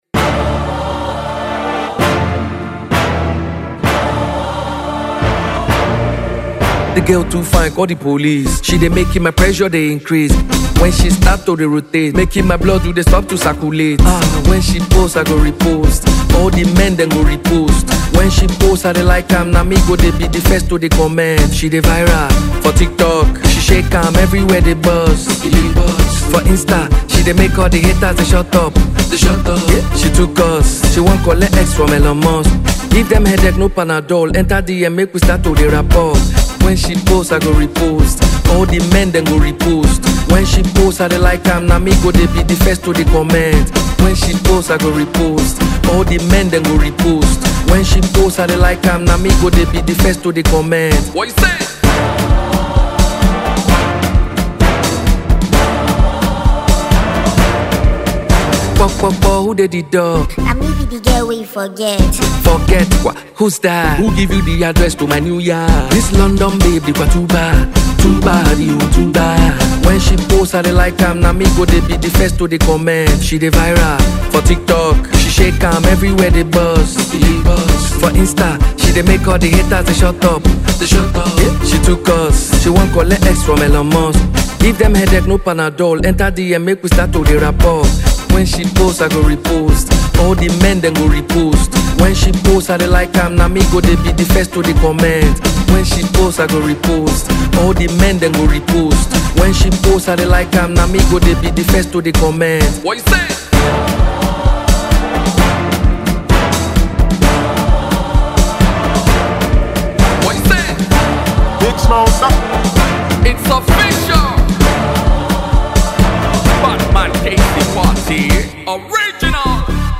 Afrobeat energy with street-inspired rhythms